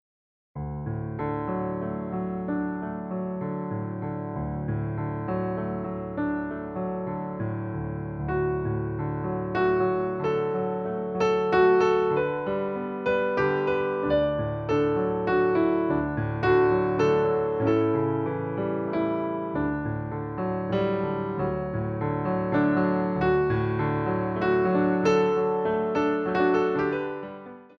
Plié on a 3/4